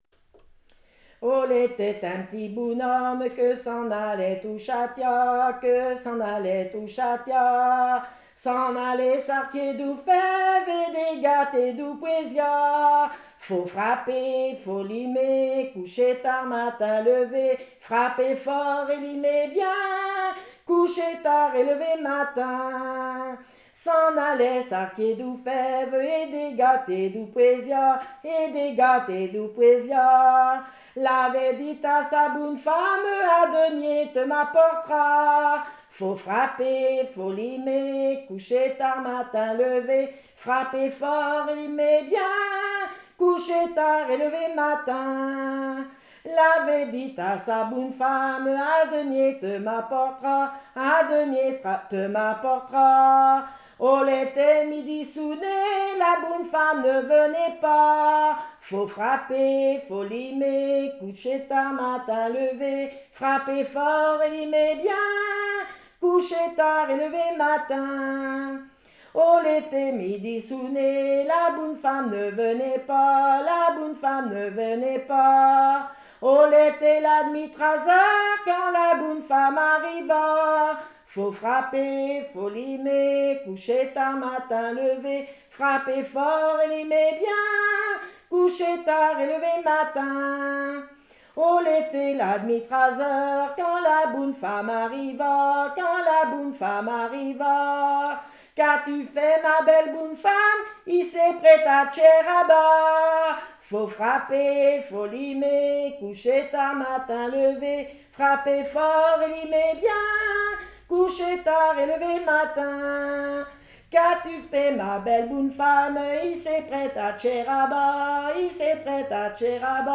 danse : ronde : demi-rond
Genre laisse
chansons traditionnelles islaises